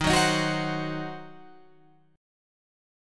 D#m6 chord